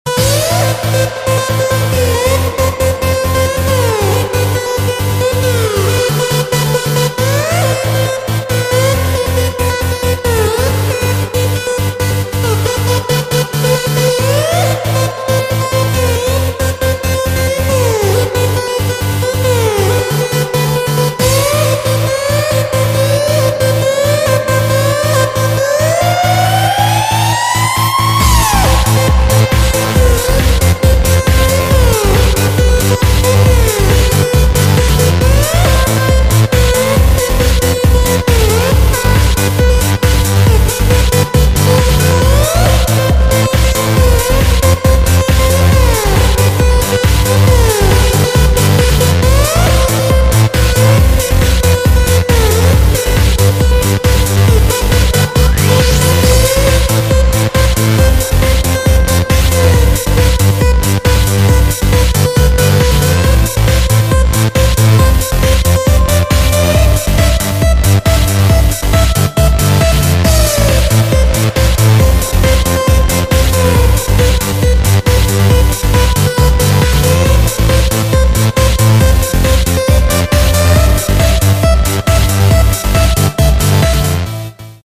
• Качество: 128, Stereo
громкие
Electronic
электронная музыка
без слов
club
electro house